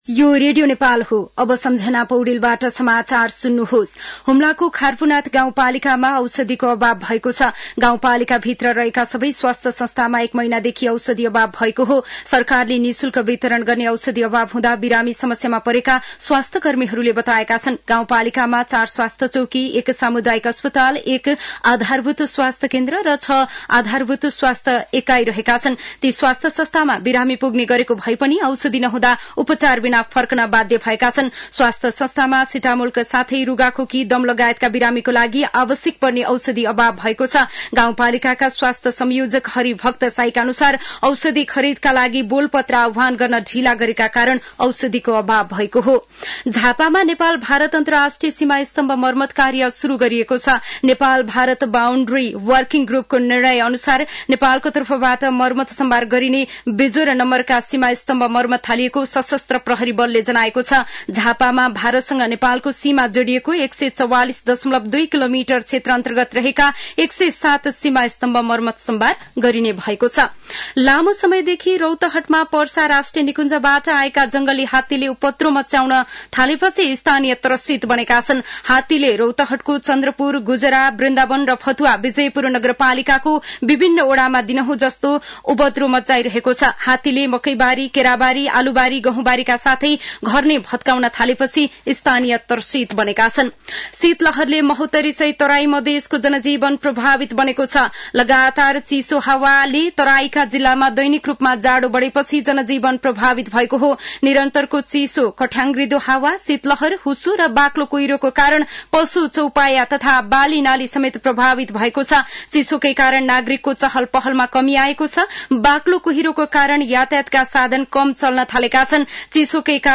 दिउँसो १ बजेको नेपाली समाचार : २७ पुष , २०८१